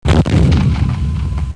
Bomb00.mp3